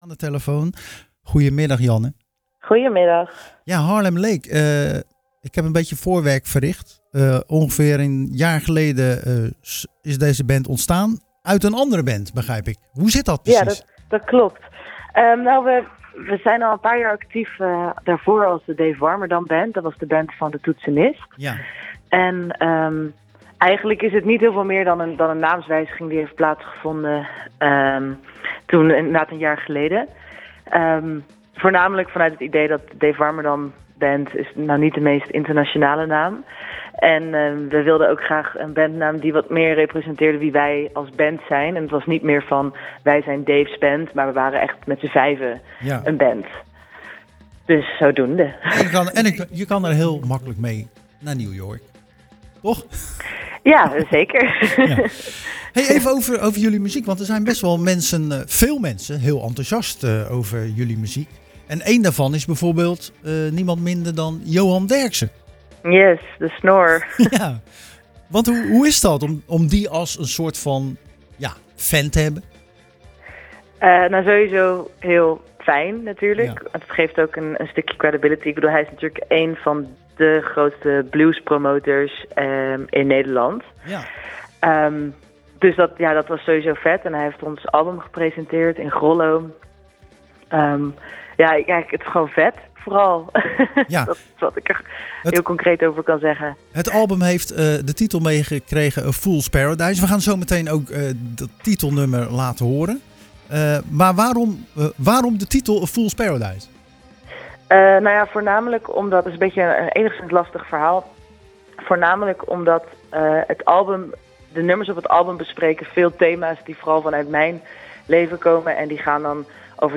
Tijdens het programma Zwaardvis belde we met singer-songwriter